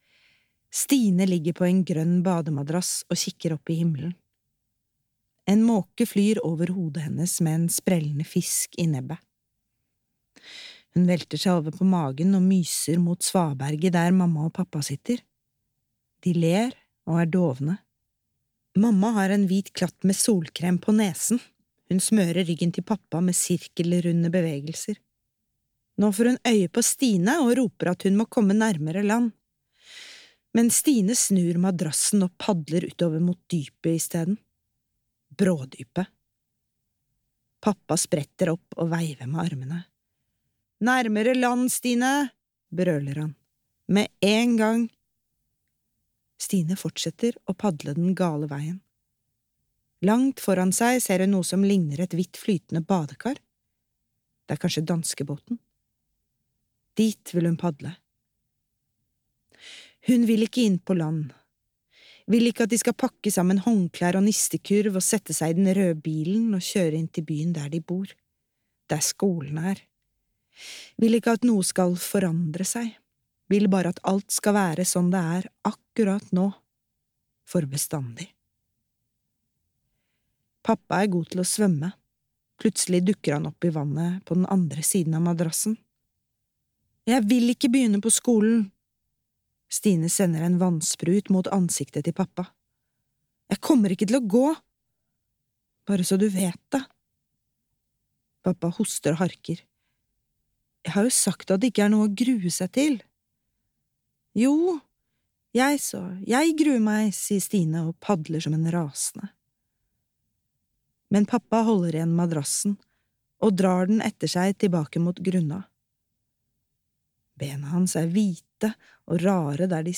Stine klasse 1A - klasse 1A (lydbok) av Tania Kjeldset